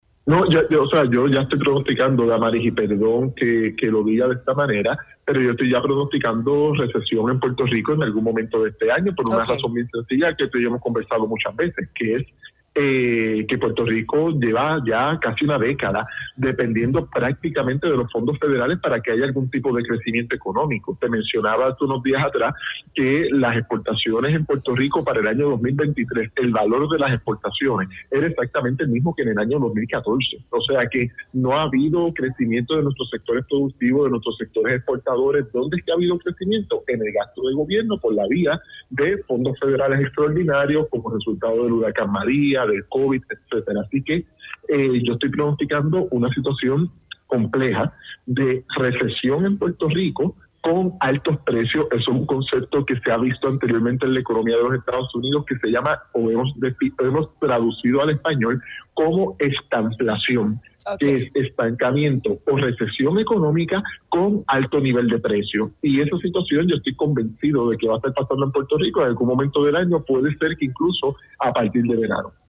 en entrevista para Dígame la Verdad